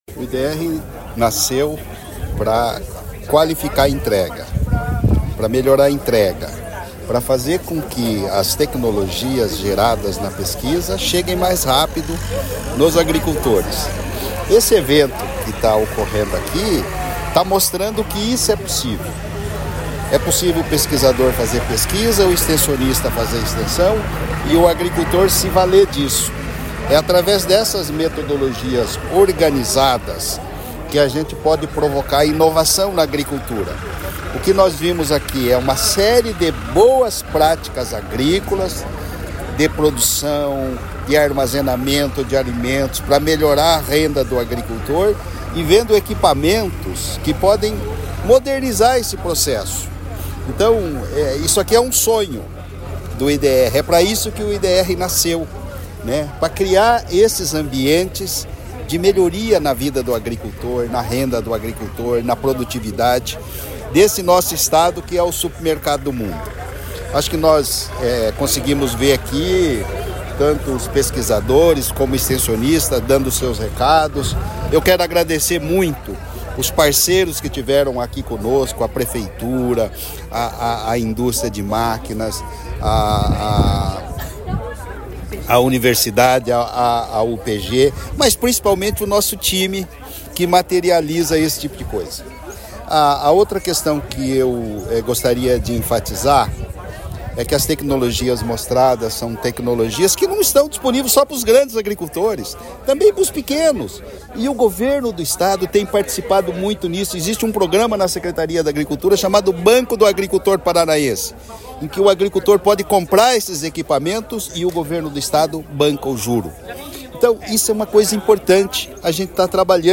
Sonora do diretor-presidente do IDR-Paraná, Natalino Avance de Souza, sobre a II Mostra Tecnológica da Fazenda-Modelo em Ponta Grossa | Governo do Estado do Paraná